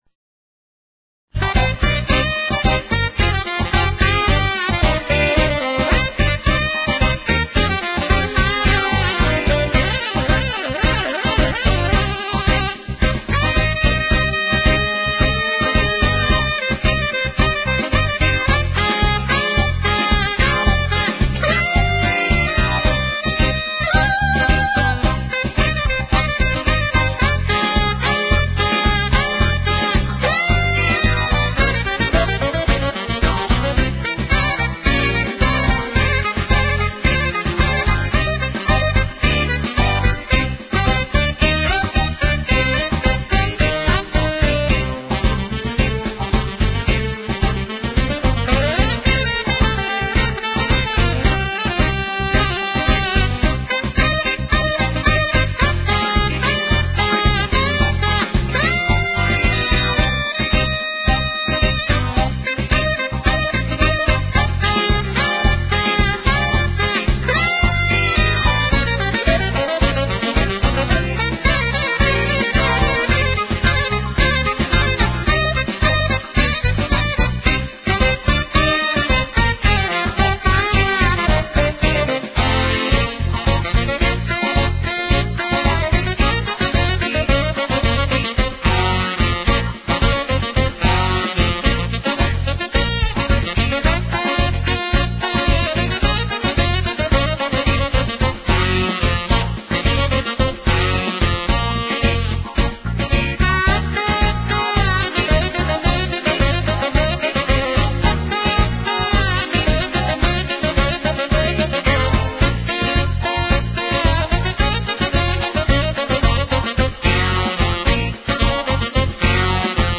Motivi Celebri - Sax